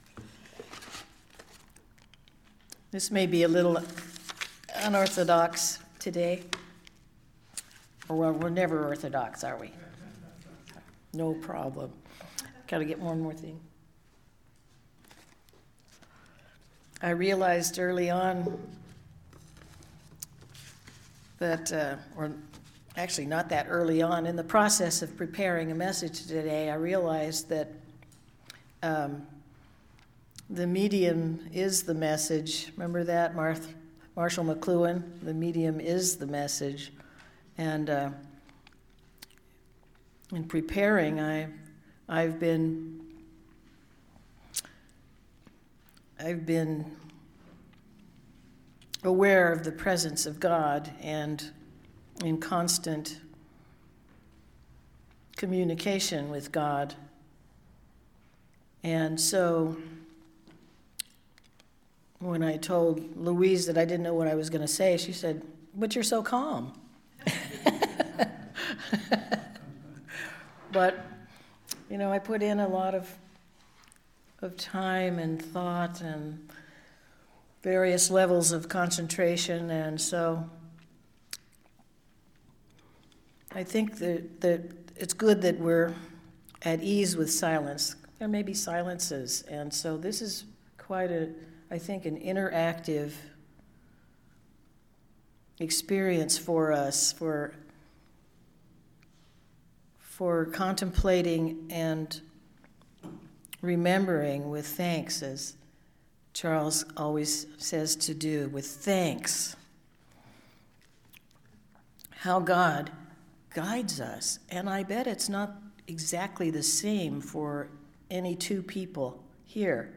Listen to the most recent message from Sunday worship at Berkeley Friends Church, “With God As Your Guide.”